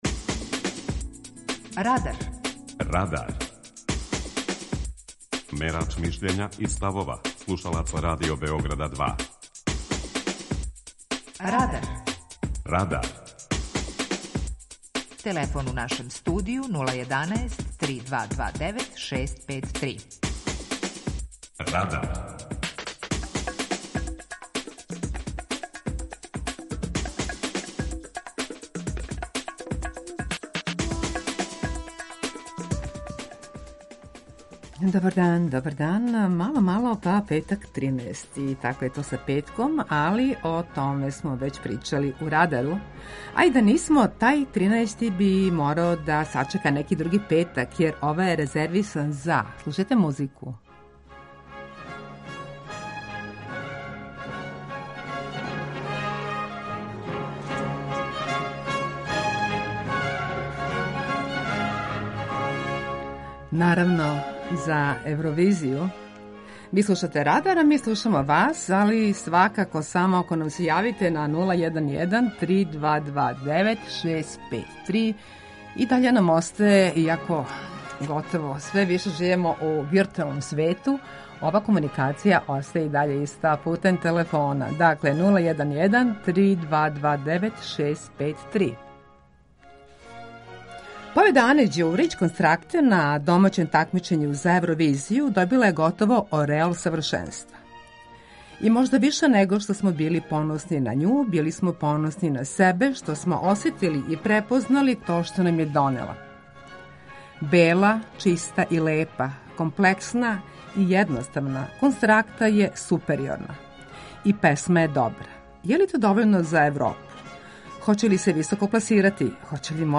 Питање Радара је: Да ли је Евровизија исполитизована? преузми : 18.96 MB Радар Autor: Група аутора У емисији „Радар", гости и слушаоци разговарају о актуелним темама из друштвеног и културног живота.